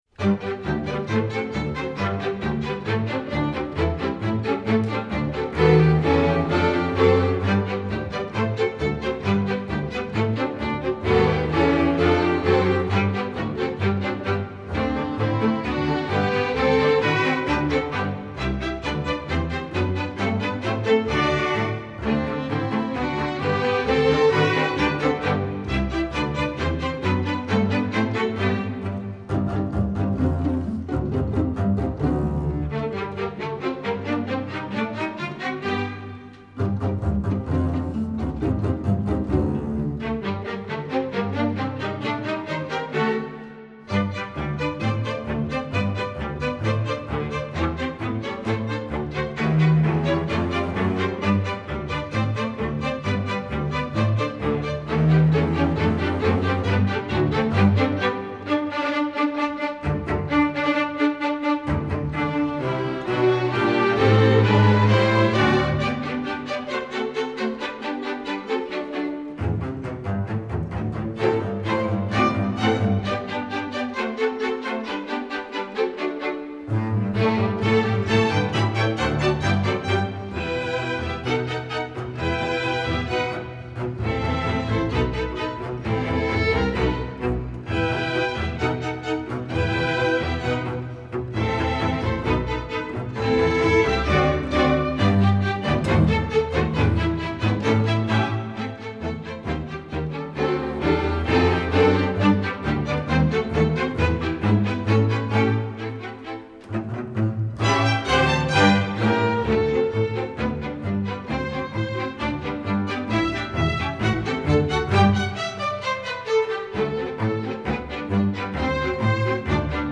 Instrumentation: string orchestra